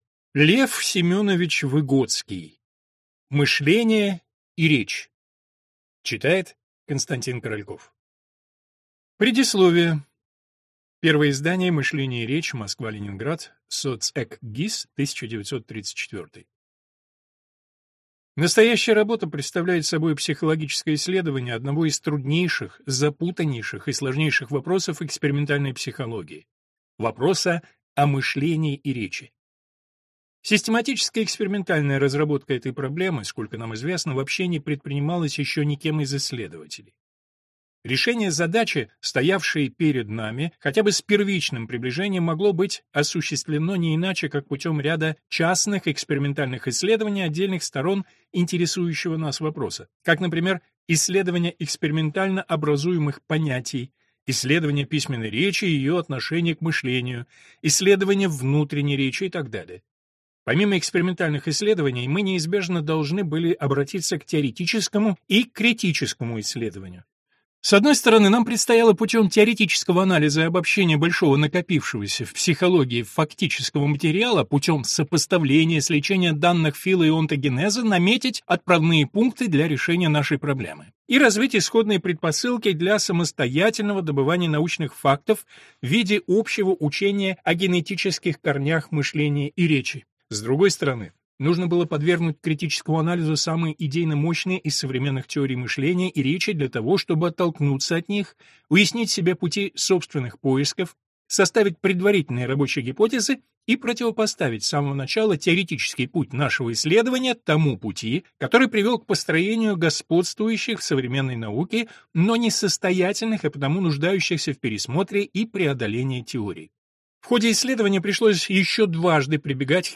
Аудиокнига Мышление и речь | Библиотека аудиокниг